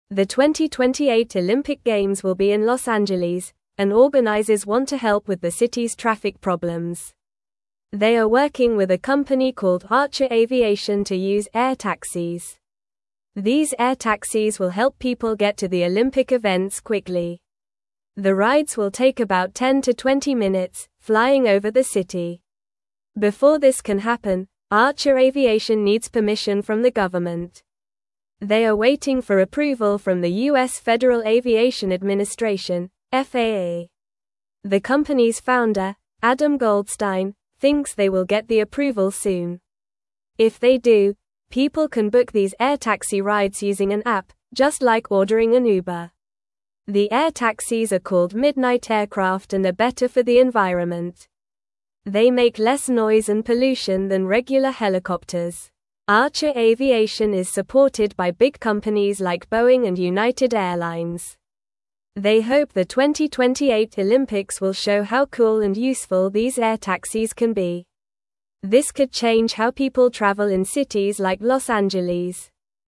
Normal
English-Newsroom-Lower-Intermediate-NORMAL-Reading-Flying-Taxis-Could-Help-at-the-2028-Olympics.mp3